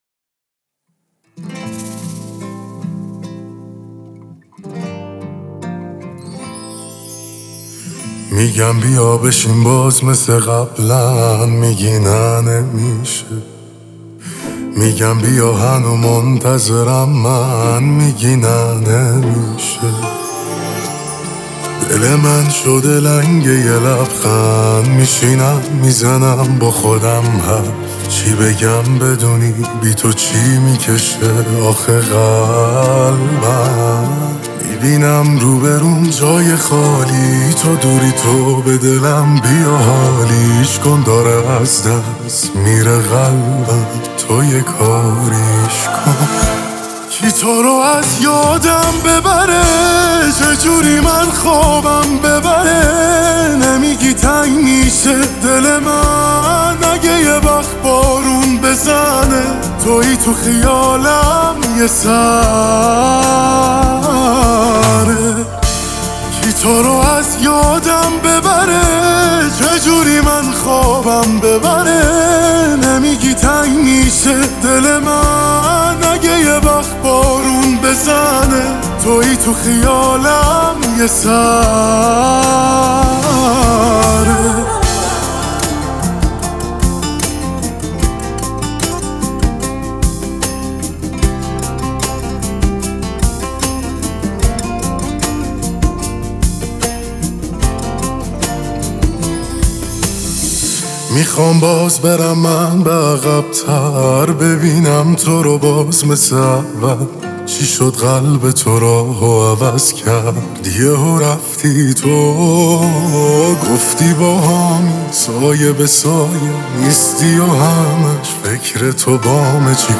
آهنگ غمگین و دلنشین